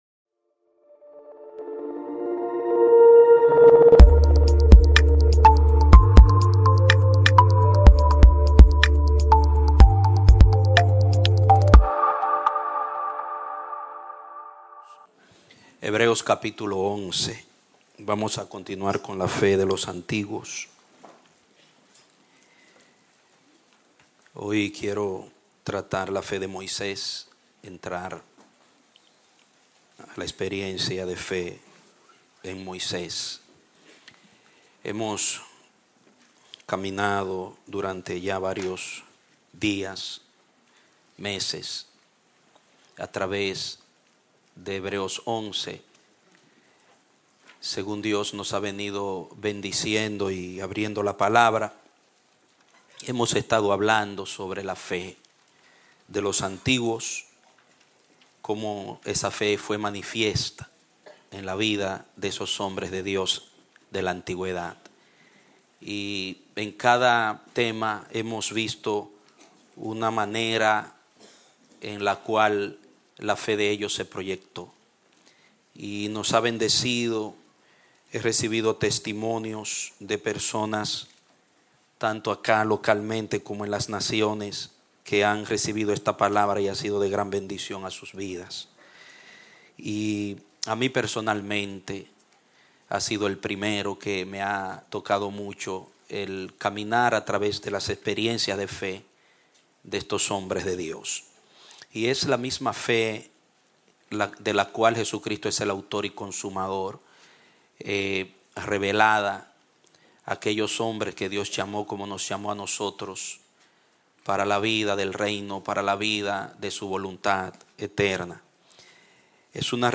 Prédicas – El Amanecer de la Esperanza Ministry
Predicado Domingo 18, de Noviembre, 2018